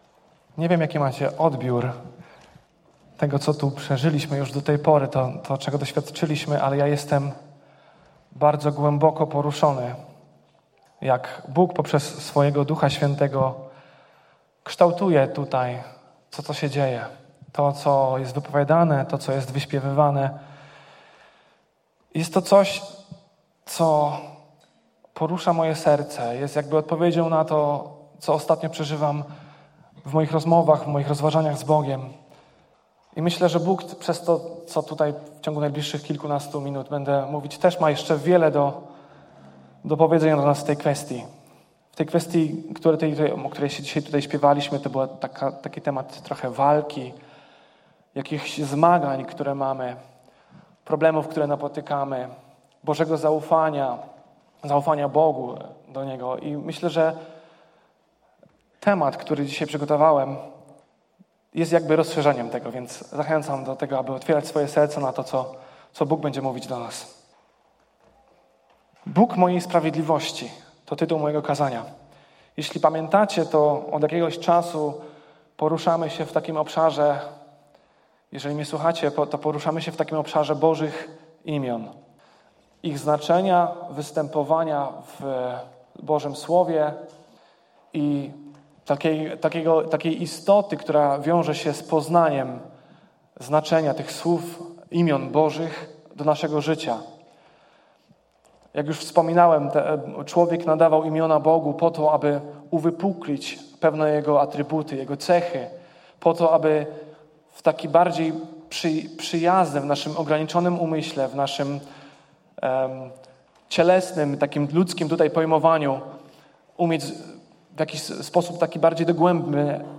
Pytania do przemyślenia po kazaniu: 1) Czy dostrzegasz w swoim sercu potrzebę zmiany pojmowania Bożej sprawiedliwości i zrozumienia, że łaska dostępna jest dla każdego, nawet dla twoich nieprzyjaciół?